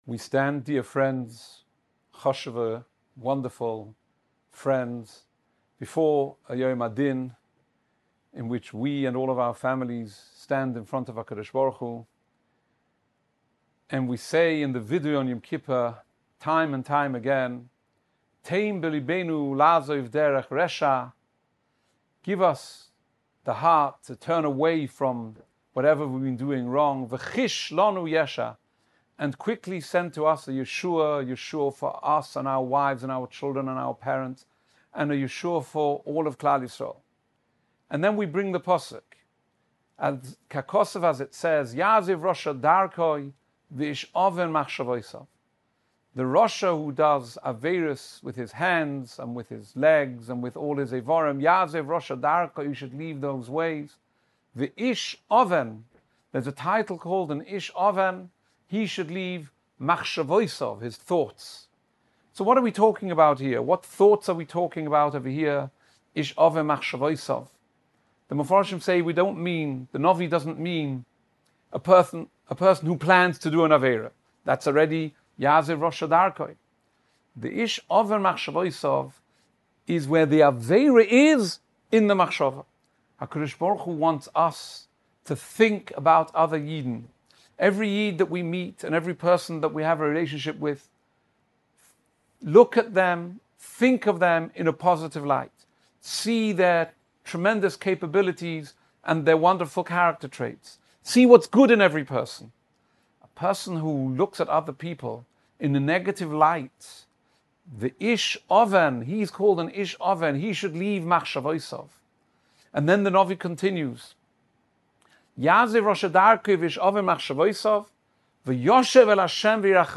Shiurim